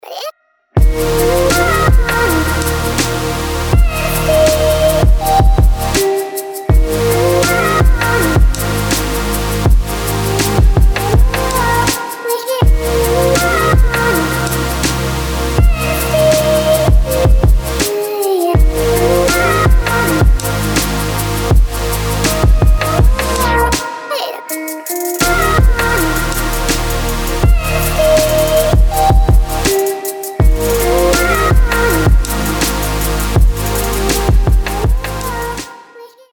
• Качество: 320, Stereo
красивые
dance
Electronic
EDM
спокойные
future bass
детский голос